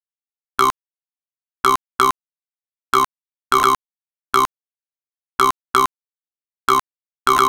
Lis Vox Hits.wav